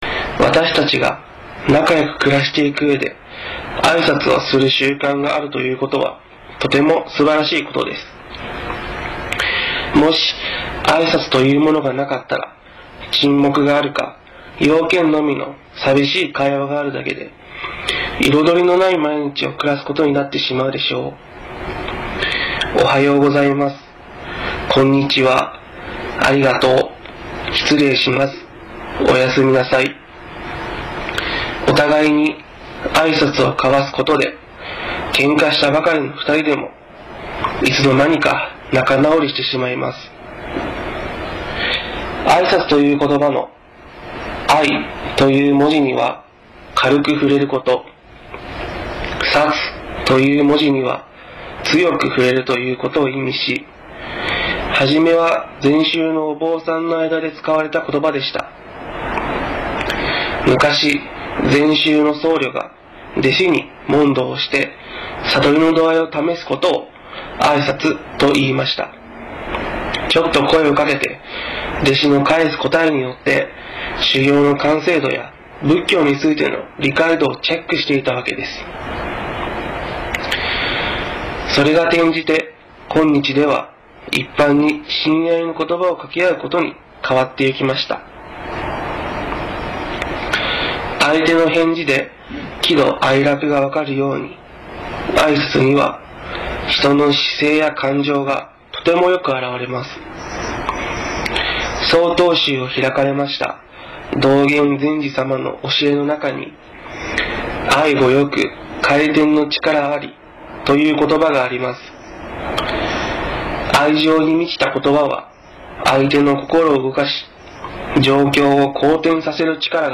テレフォン法話
曹洞宗岐阜宗務所では電話による法話の発信を行っています。